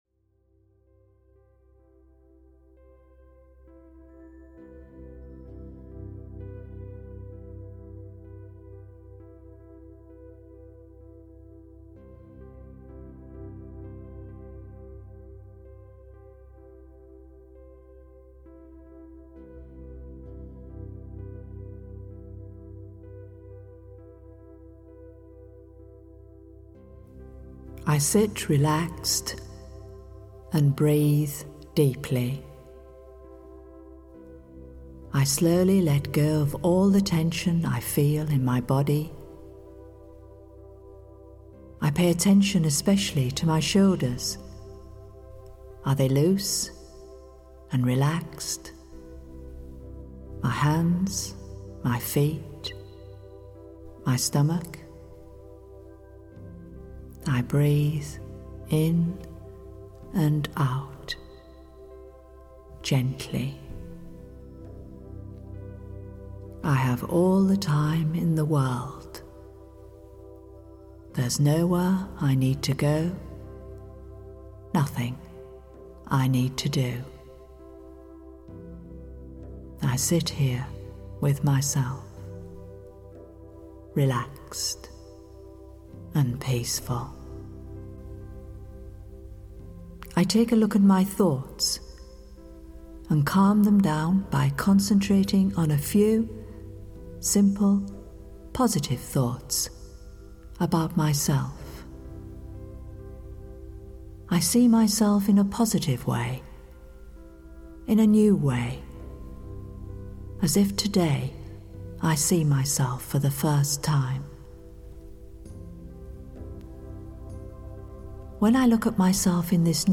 Ukázka z knihy
"Positive Thinking" by Brahma Khumaris offers its listeners a guided, musical meditation to help them unwind, relax, and let go of their stress. Follow the instructions and you will soon feel like a weight has been lifted from your shoulders and that your mind has become quieter and clearer.